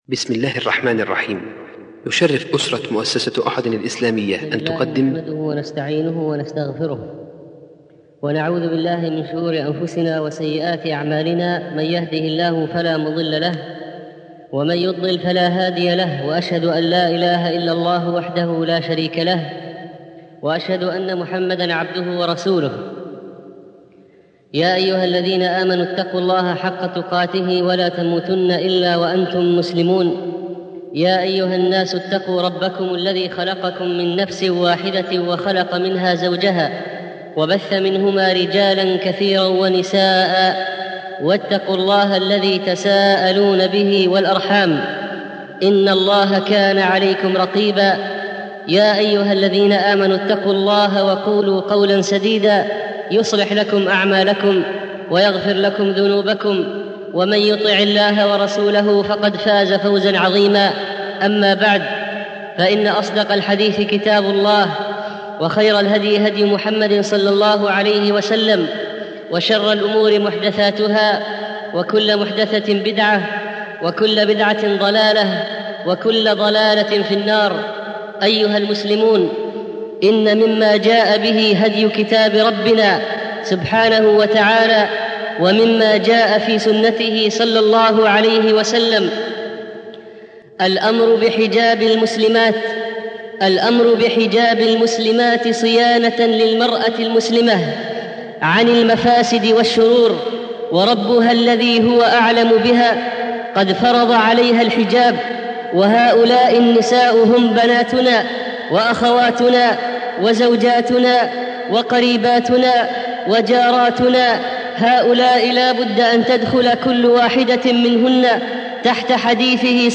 المحاضرات